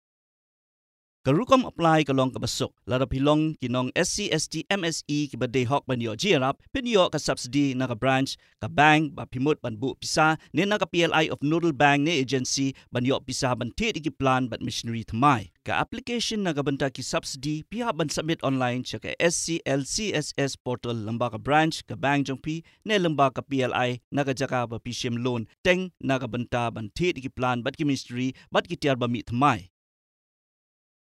Khasi Male
Khasi Male 3......